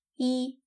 イー